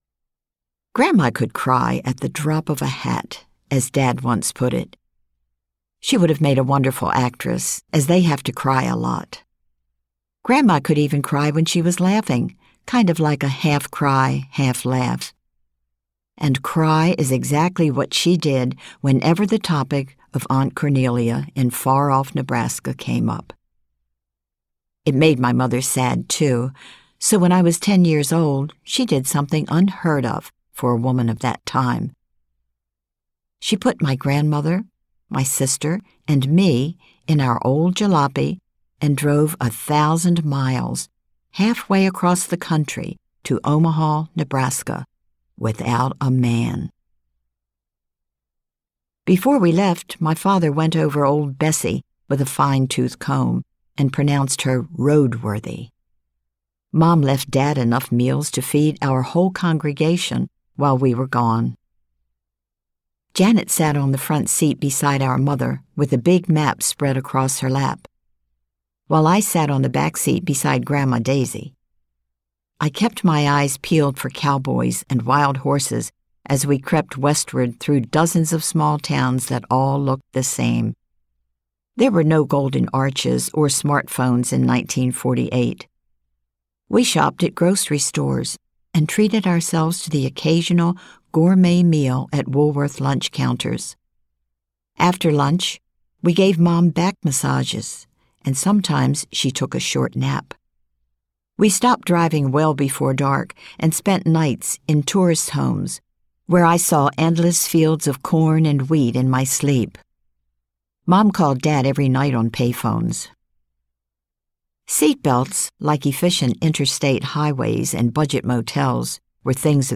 About My Mother Audiobook